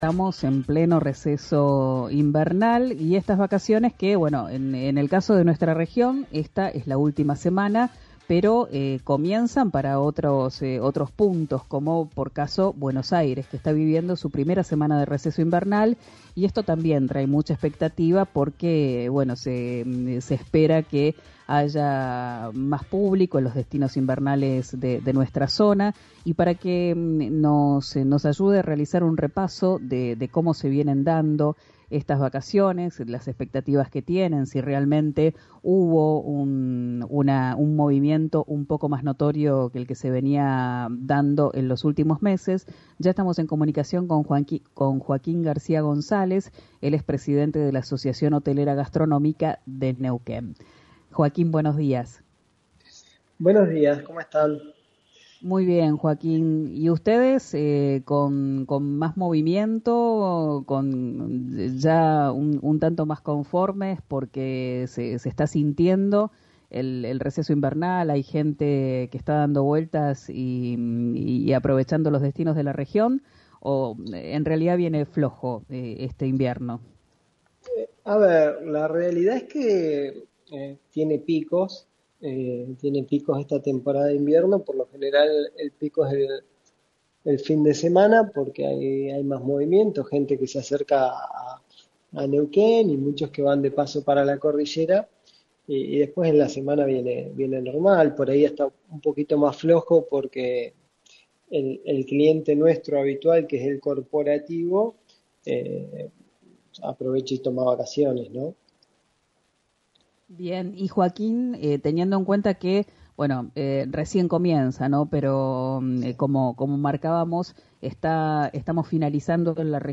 en diálogo con Río Negro Radio